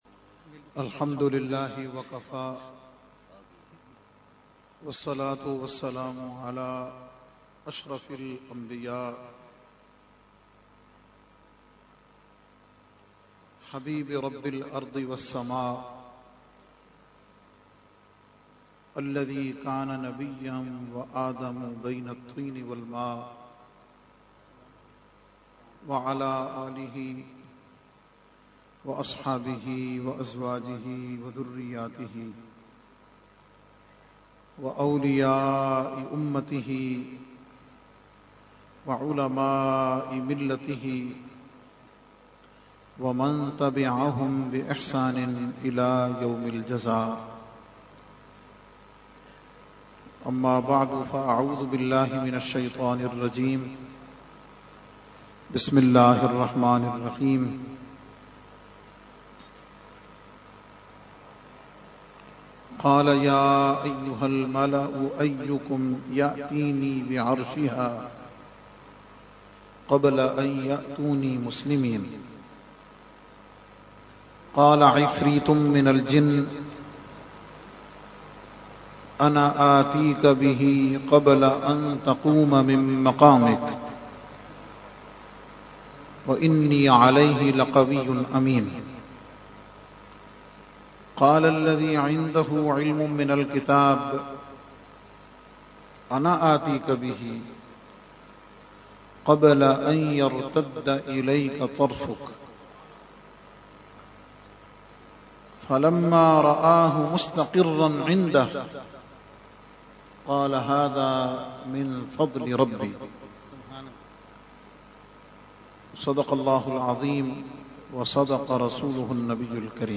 Category : Speech | Language : UrduEvent : Urs Makhdoome Samnani 2018